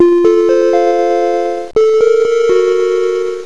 Crash Sounds:
Crash_Mac_Quadra .....................Macintosh LC 475